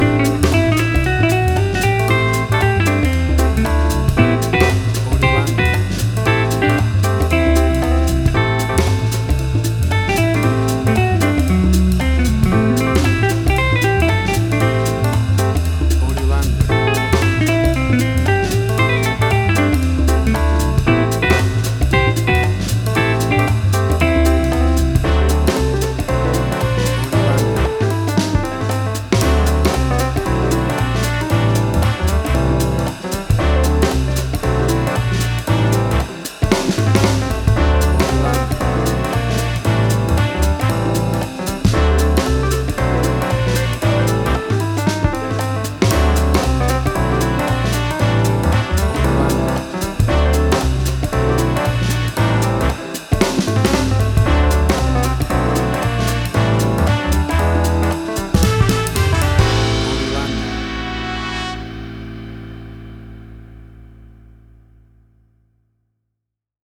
A swinging blend of the Jazz music genres and styles
WAV Sample Rate: 16-Bit stereo, 44.1 kHz
Tempo (BPM): 115